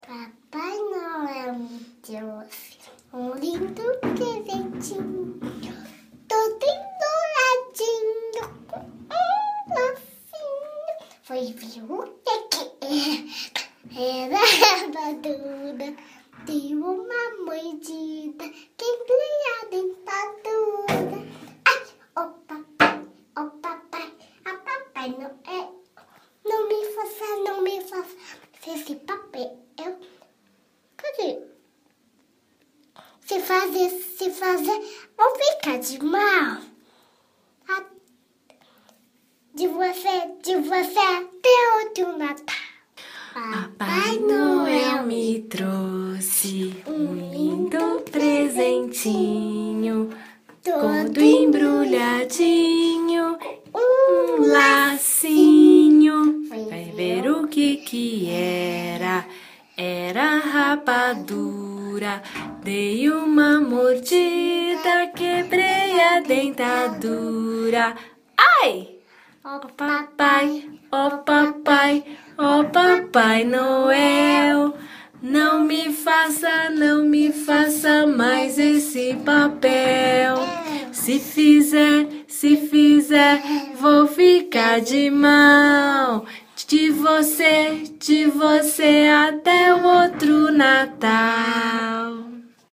Deixa eu já avisar que são paródias divertidas.